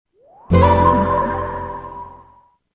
SMS Alert
Boot sound of a PSP UMD disc